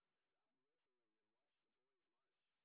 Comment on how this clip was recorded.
sp23_white_snr30.wav